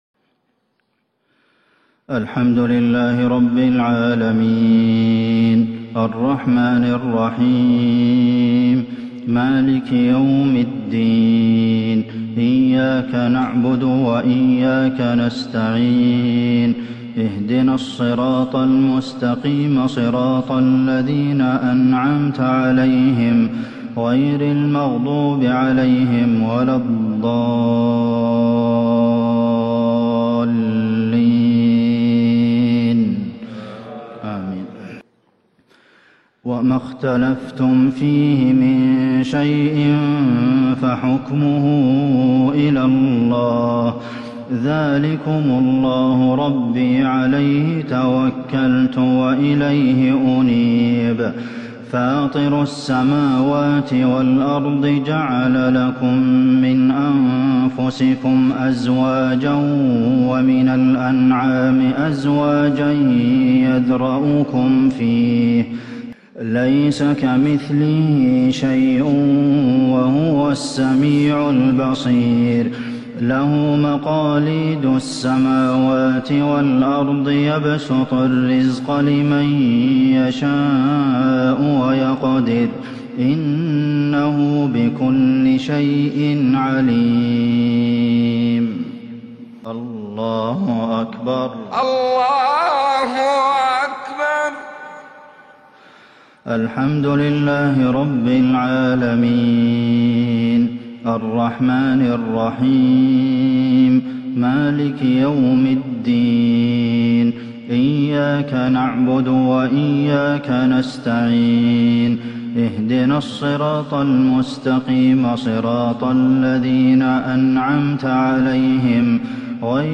مغرب الجمعة 3-5-1442هـ من سورة الشورى | Maghrib prayer from Surah ash-Shura 18/12/2020 > 1442 🕌 > الفروض - تلاوات الحرمين